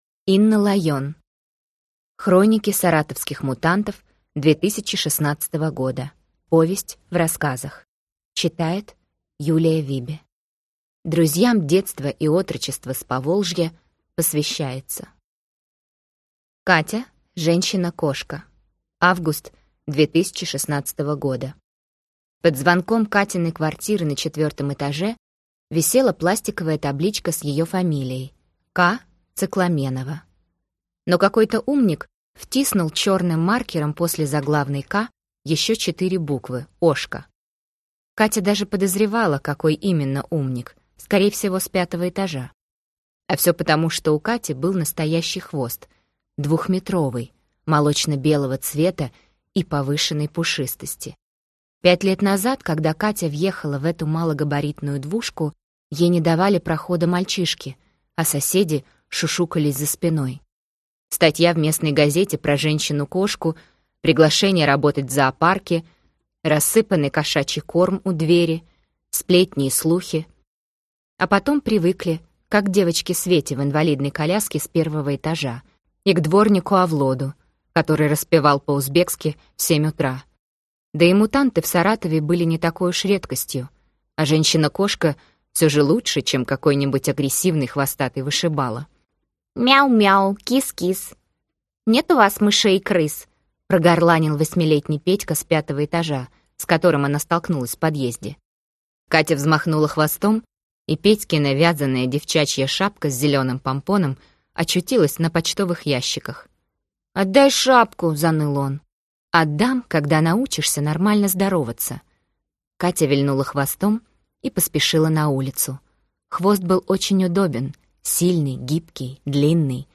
Аудиокнига Все начинается с хвоста. Повесть в рассказах | Библиотека аудиокниг